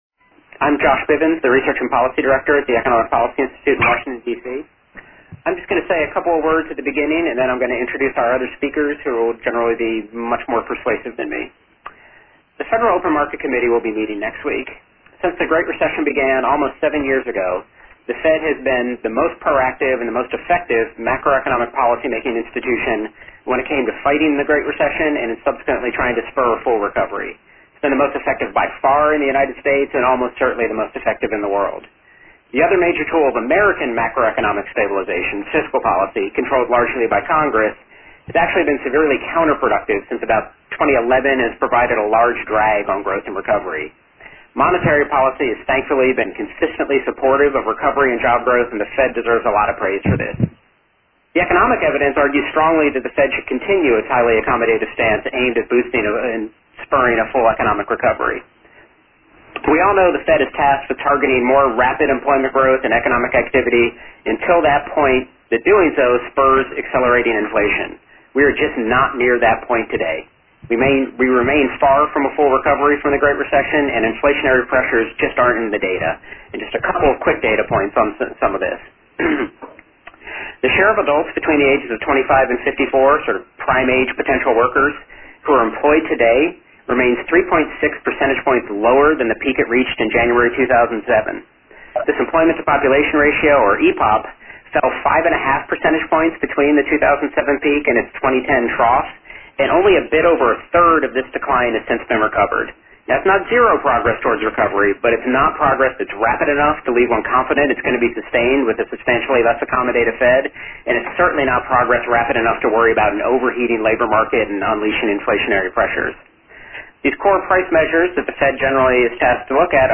At a media conference call last month sponsored by the Economic Policy Institute